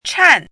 chinese-voice - 汉字语音库
chan4.mp3